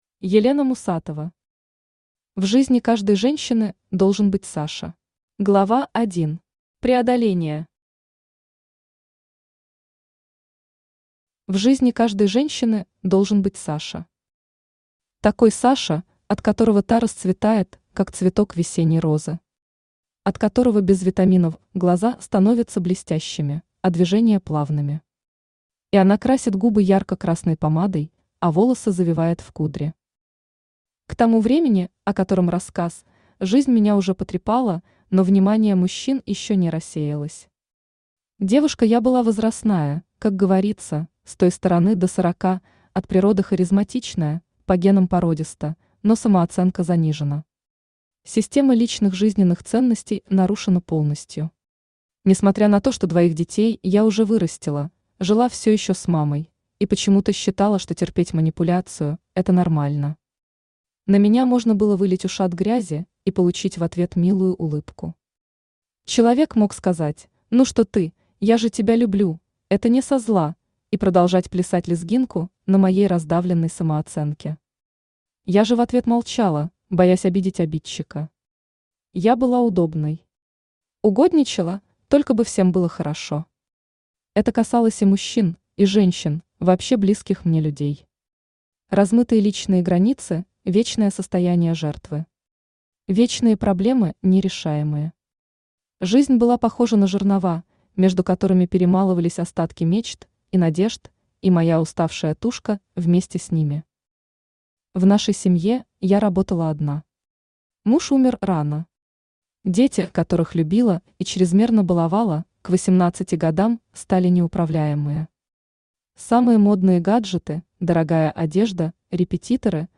Аудиокнига В жизни каждой женщины должен быть «Саша» | Библиотека аудиокниг
Aудиокнига В жизни каждой женщины должен быть «Саша» Автор Елена Мусатова Читает аудиокнигу Авточтец ЛитРес.